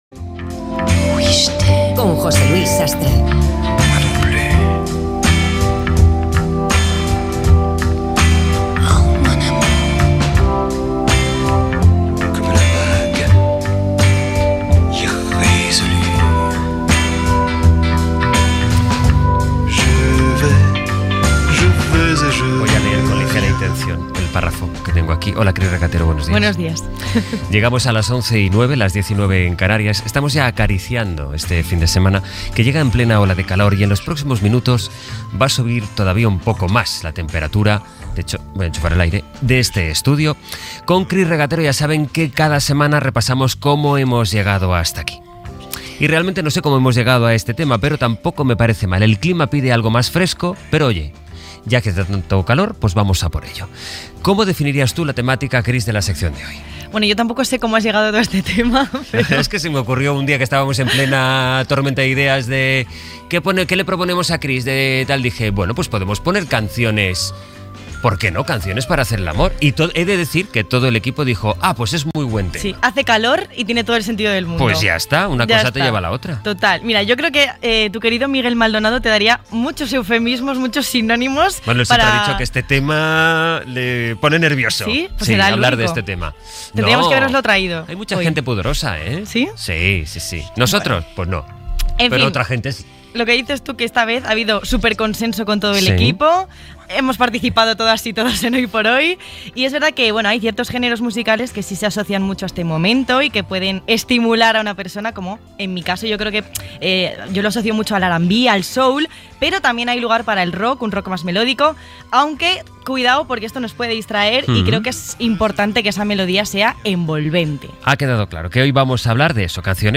Cançons per fer l'amor. Comentaris inicials i recull de cançons d'estils diferents
Info-entreteniment